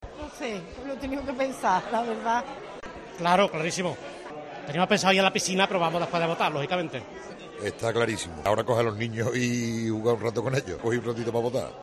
Sonidos de los votantes cordobeses en el 19-J